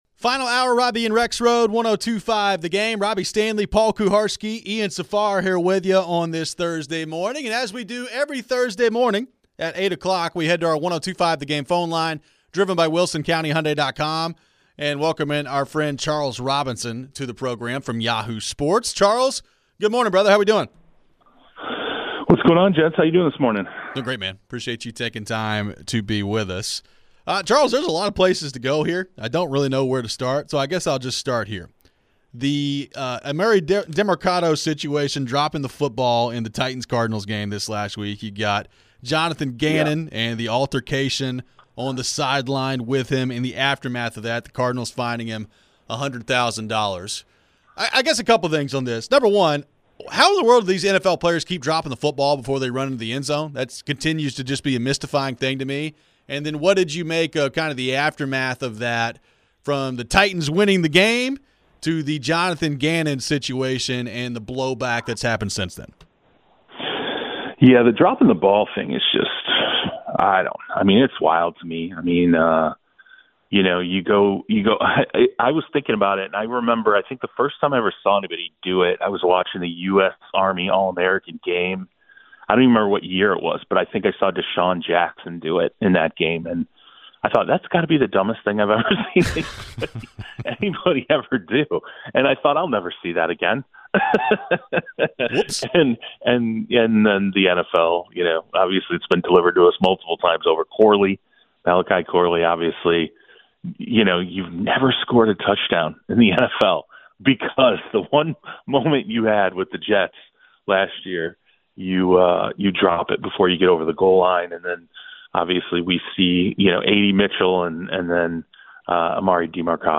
Interview
We wrap up the show with your final phones.